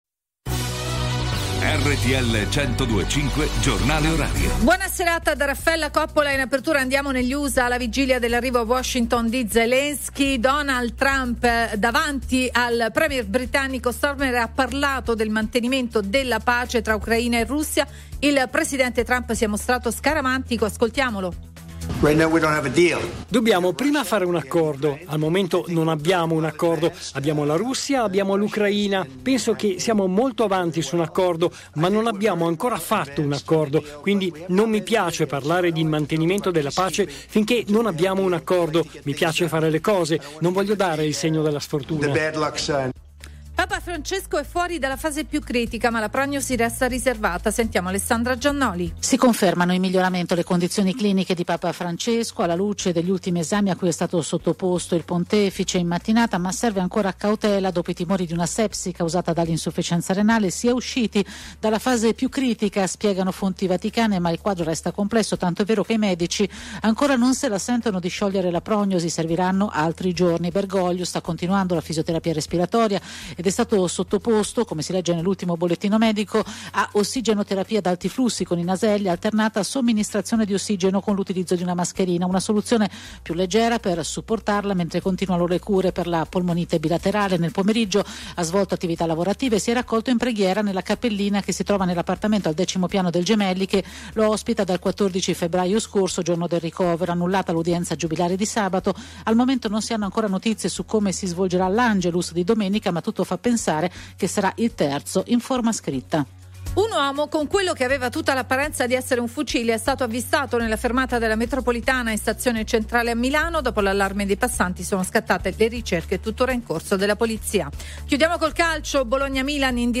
Il giornale orario di RTL 102.5 a cura della redazione giornalistica